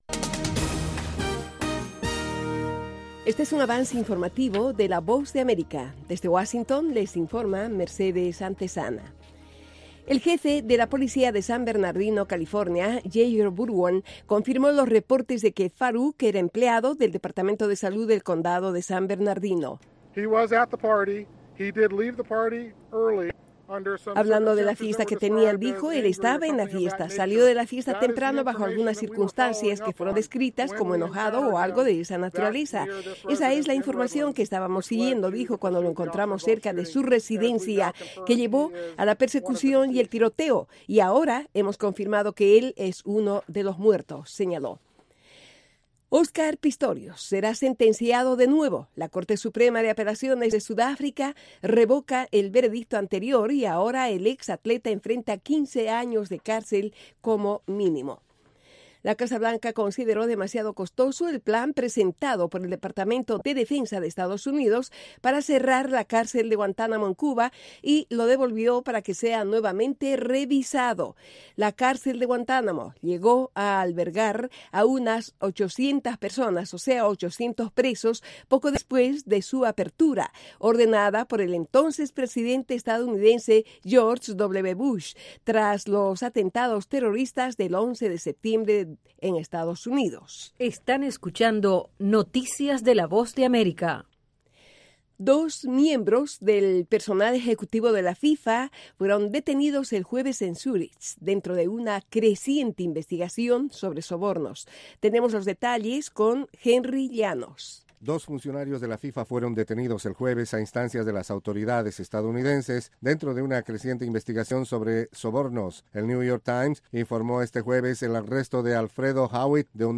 Capsula informativa de tres minutos con el acontecer noticioso de Estados Unidos y el mundo.